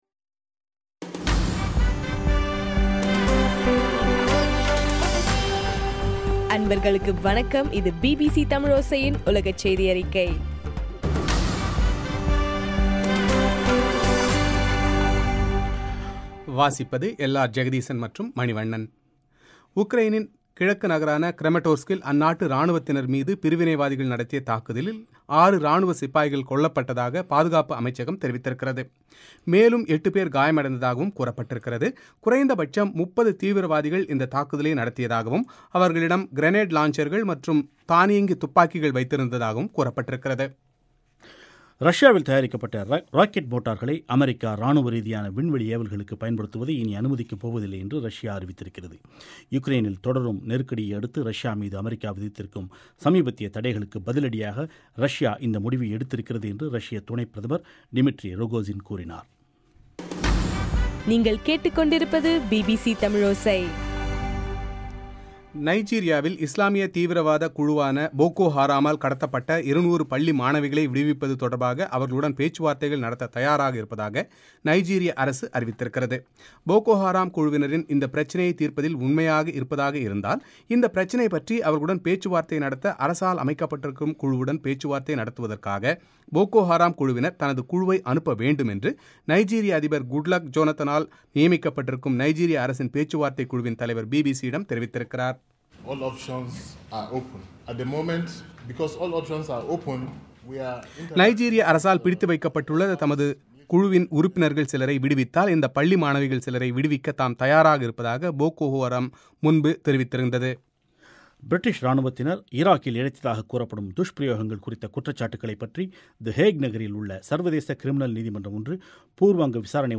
மே 13 பிபிசியின் உலகச் செய்திகள்